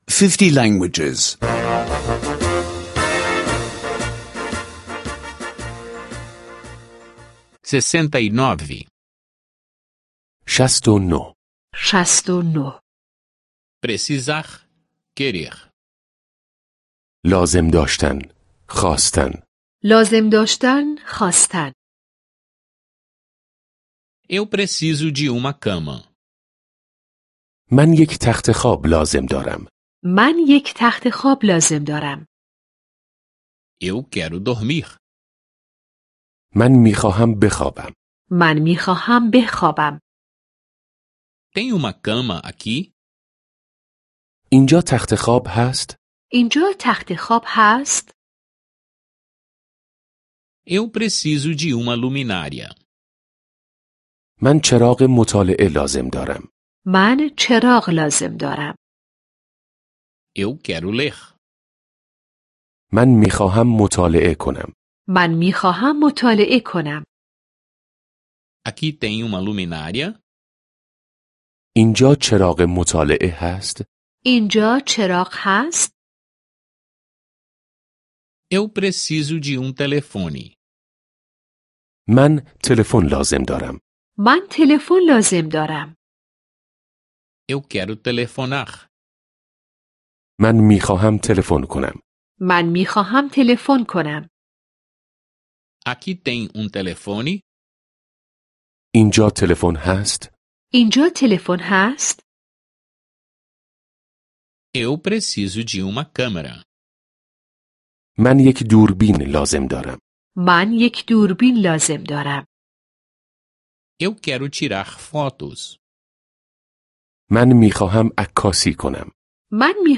Aulas de persa em áudio — download grátis